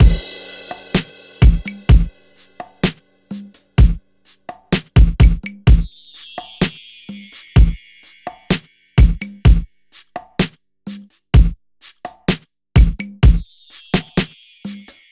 14 drums C2.wav